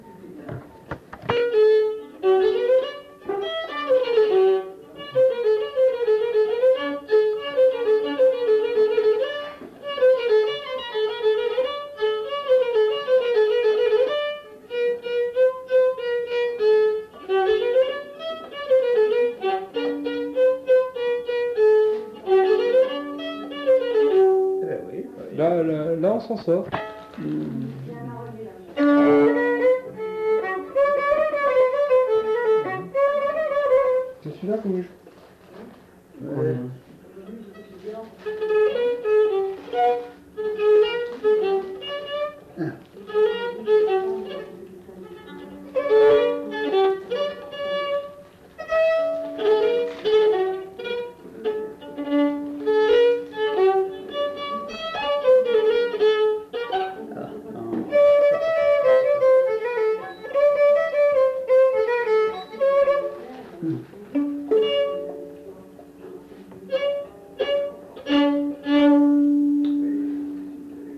Lieu : Saint-Michel-de-Castelnau
Genre : morceau instrumental
Instrument de musique : violon
Danse : congo
Notes consultables : En fin de séquence, il essaie de se remémorer un autre air.